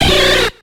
Cri de Caratroc dans Pokémon X et Y.